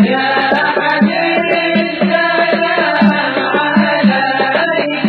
Can someone please remove the background drum beats for me?
To save others the time and bandwidth of downloading the 7.5 MB MP3, here’s a short clip of a relevant part: